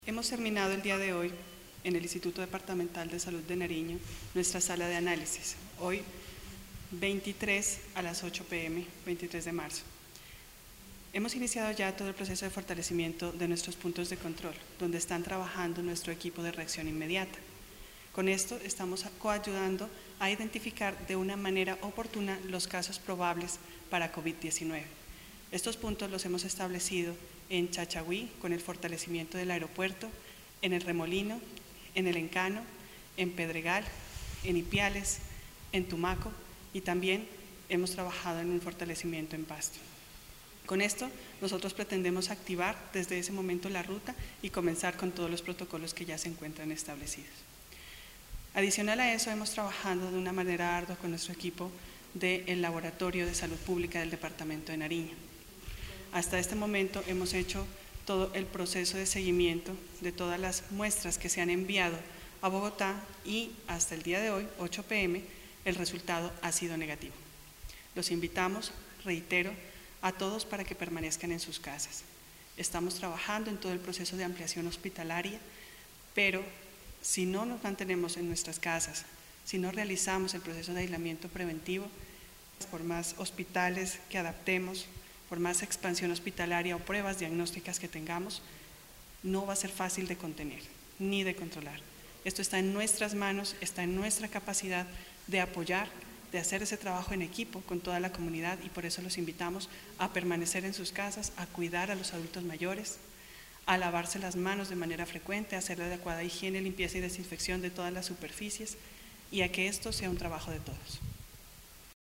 Al término de la Sala de Análisis de Nariño por la contingencia del Coronavirus, la Gerente del Instituto Departamental de Salud de Nariño – IDSN, Diana Paola Rosero, informó que a corte del lunes 23 de marzo a las 8:00 de la noche, Nariño no registra casos positivos de COVID-19. La Directora hizo un llamado a la comunidad para que continúe acatando la medida de aislamiento preventivo y la puesta en práctica de las medidas de autocuidado.
Dir-IDSN-Diana-Paola-Rosero.mp3